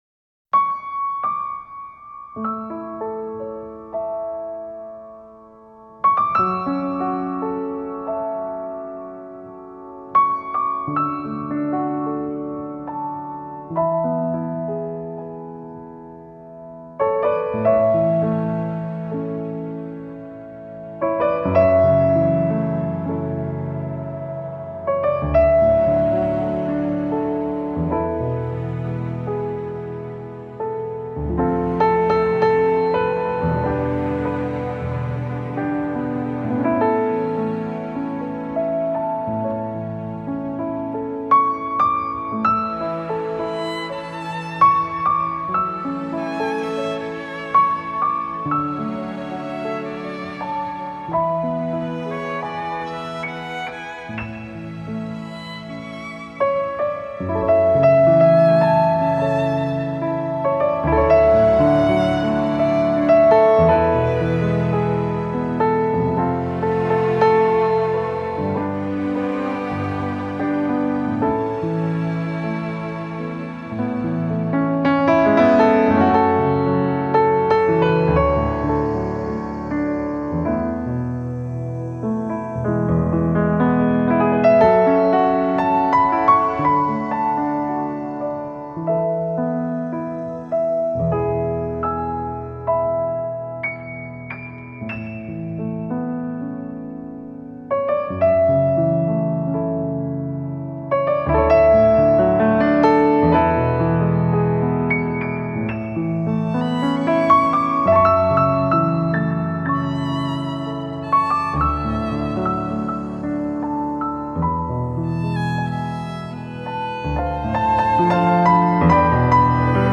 名家钢琴
它用情感丰沛的双手弹琴
这张专辑乐风比上一张要更静谧，聆听几分钟後，它就会融入你身边的事物，当你在进行其他事的同时，提供一个宁静而舒适的背景。
这张专辑无需用华丽的钢琴技巧来讨好耳朵，仔细聆听，相信你会对这张专辑爱不释手。